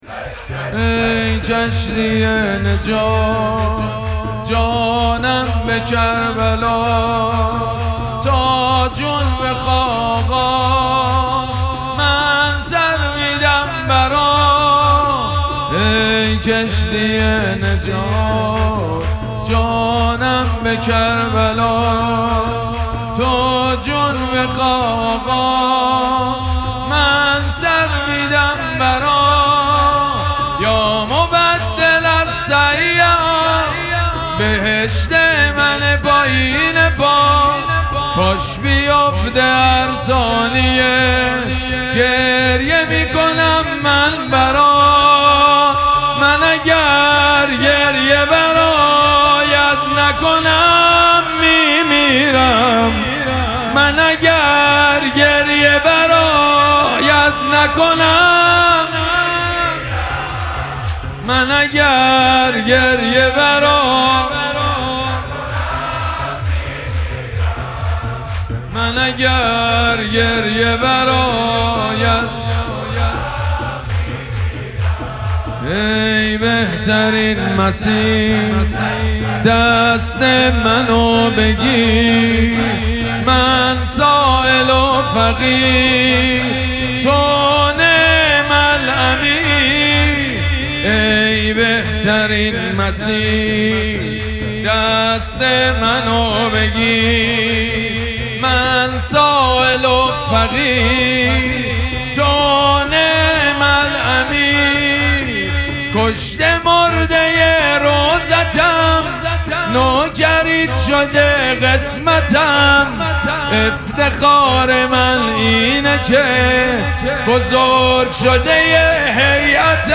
مراسم مناجات شب نوزدهم ماه مبارک رمضان
حسینیه ریحانه الحسین سلام الله علیها
شور
مداح
حاج سید مجید بنی فاطمه